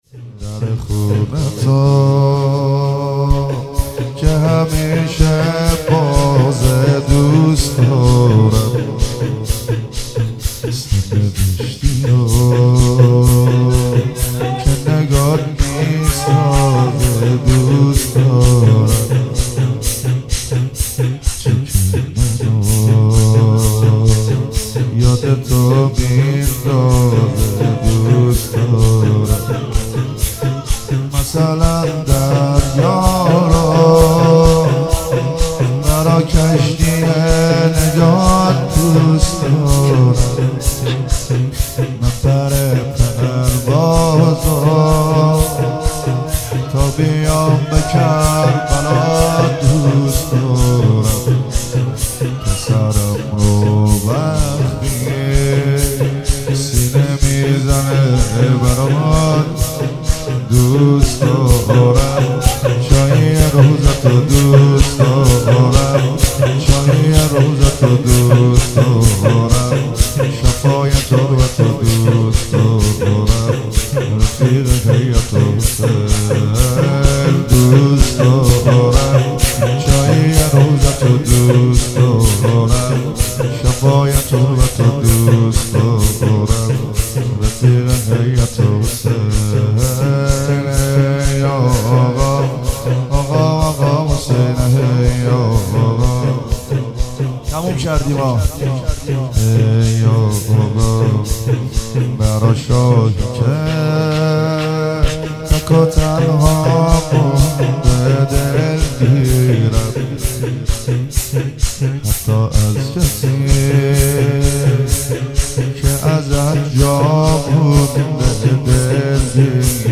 نوحه ویژه